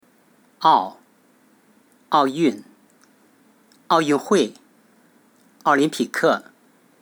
這個理論雖然不完全正確，但也說明了一個問題，就是詞語中每個音節的長短並不是平均的，在此我做了個實驗，以正常語速分別讀單音節；雙音節；三音節及四音節詞語，測試一下每個音節的長度，結果如下：
單音節：奧 0.33s
雙音節：奧運 0.53s (0.21s 0.32s)
三音節：奧運會 0.6s (0.16s 0.14s 0.2s)
四音節：奧林匹克 0.77s (0.12s 0.12s 0.16s 0.29s)